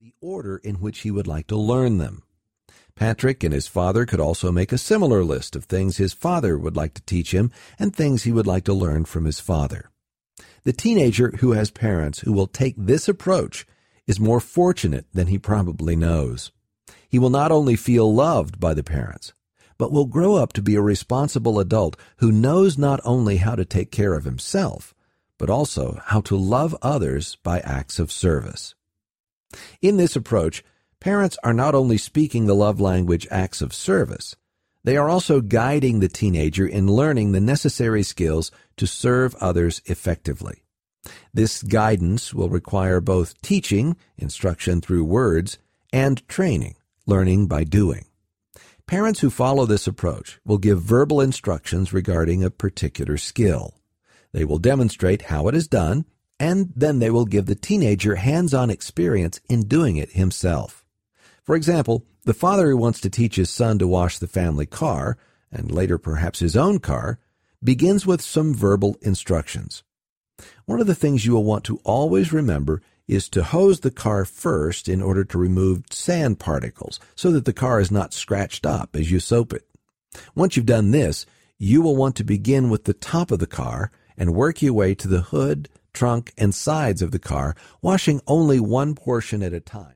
The 5 Love Languages of Teenagers Audiobook
Narrator